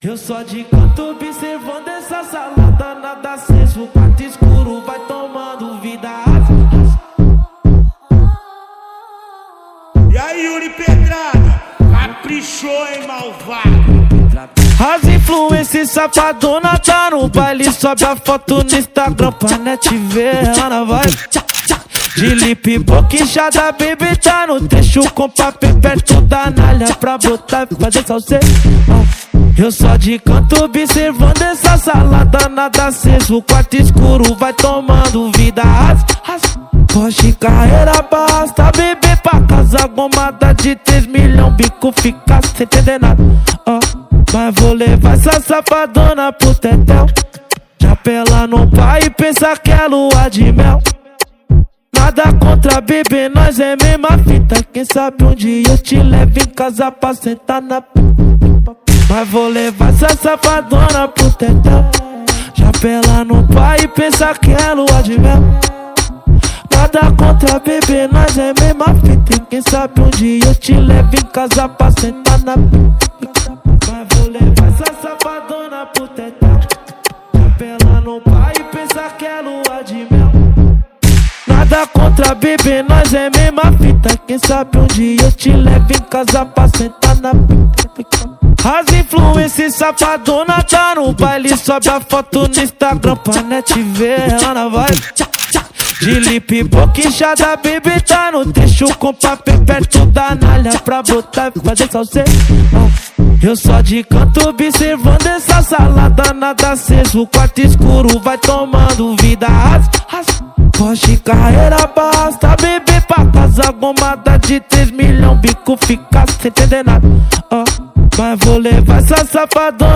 2025-03-05 04:04:26 Gênero: Trap Views